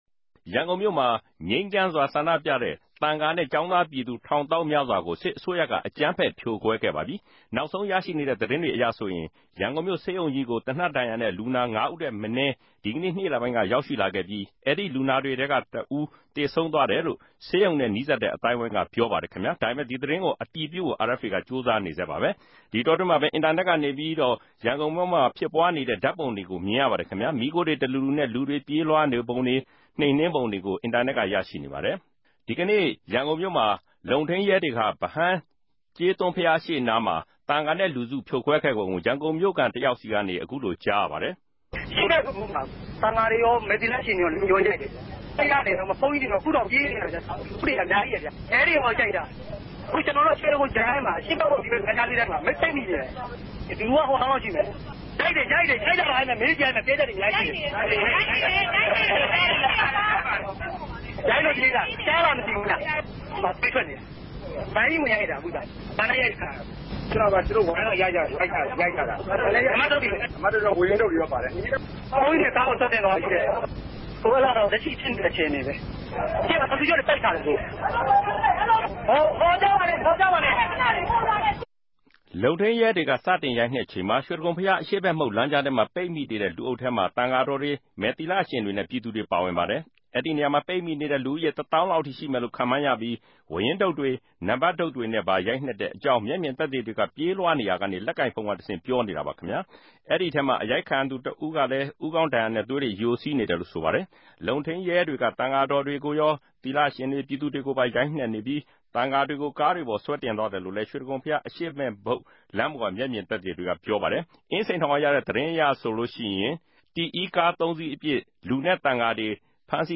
လုံထိန်းရဲတေကြ စတင်႟ိုကိံြက်ခဵိန်မြာ ေ႟တြိဂုံဘုရား အရြေႚဖက်မုခ်လမ်းုကားထဲမြာ ပိတ်မိနေတဲ့ လူအုပ်ထဲမြာ သံဃာတော်တြေ၊ သီလရင်တေနြဲႛ ူပည်သူတြေ ပၝဝင်ပၝတယ်။ ပိတ်မိနေတဲ့သူ တသောင်းလောက်ရြိမယ်လိုႛ ခန်ႛမြန်းရ္ဘပီး ဝၝးရင်းတုတ်တြေ နံပၝတ်တုတ်တေနြဲႛပၝ ႟ိုကိံြက်တဲ့အေုကာင်း မဵက်ူမင်သက်သေတေကြနေ ေူပးလြားနေရာ လက်ကိုင်ဖုန်းကတဆင့် ေူပာနေတာပၝ။ အ႟ိုက်ခံရသူတဦးဟာ ခေၝင်းမြာ ဒဏ်ရာနဲႛသြေးတြေ ယိုစီးနေတယ်လိုႛ ဆိုပၝတယ်။